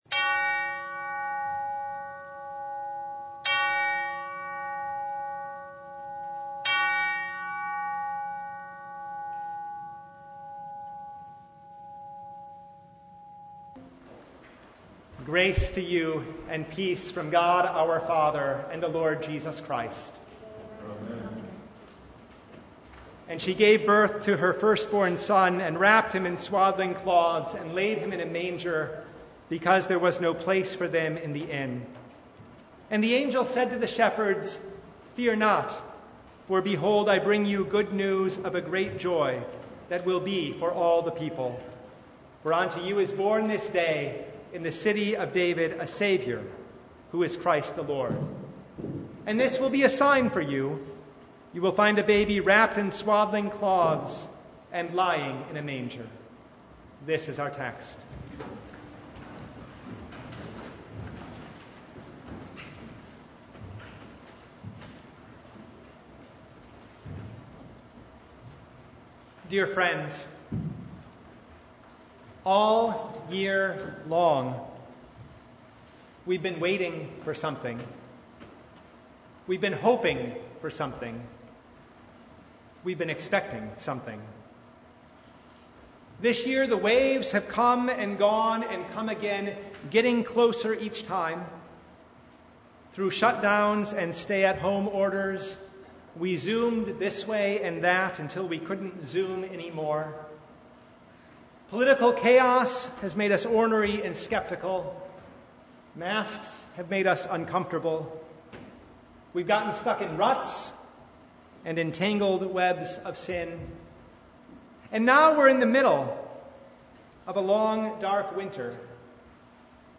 Service Type: Christmas Eve
Sermon Only